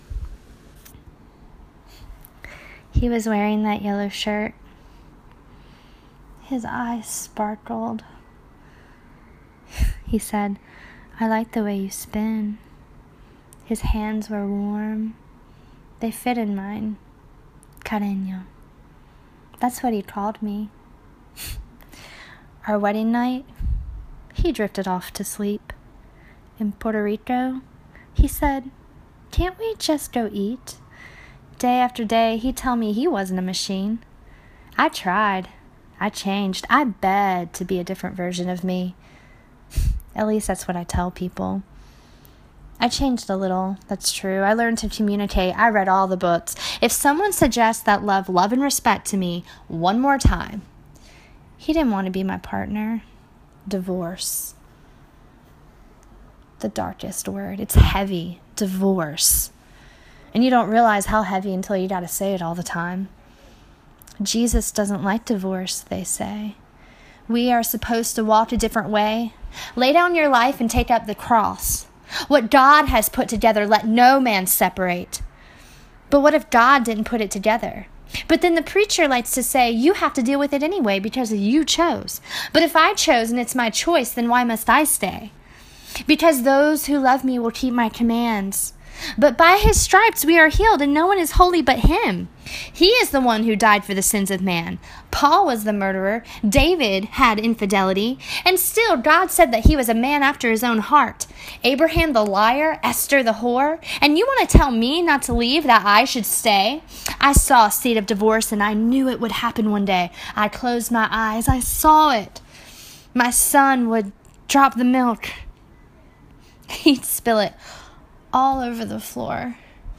This is a spoken word/poetic story that was also performed at The Moth in L.A. on Valentine’s Day in 2017. This writing expresses the depths of my feelings towards divorce and along with that my questioning religion.